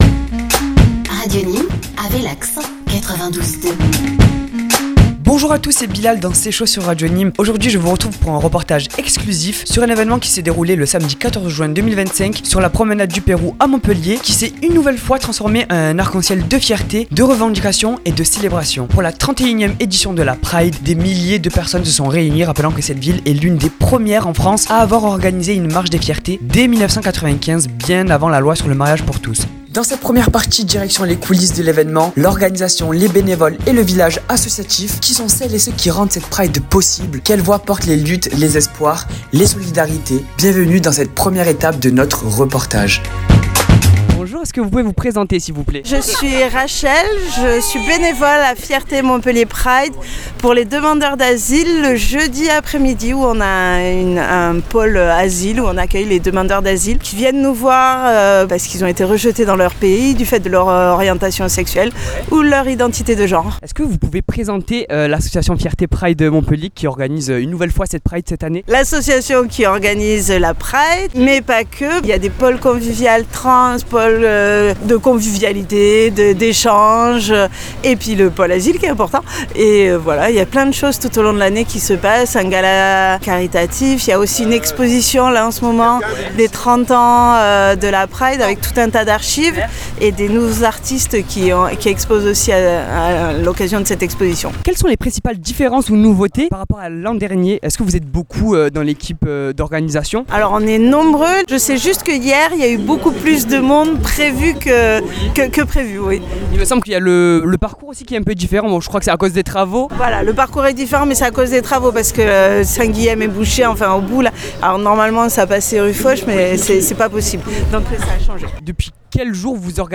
Dans cette première partie de reportage, il nous emmène dans les coulisses de l’organisation. Il donne la parole à l’association Fierté Pride Montpellier, en charge de l’événement, pour évoquer les enjeux, les préparatifs, les évolutions…